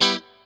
CHORD 2   AB.wav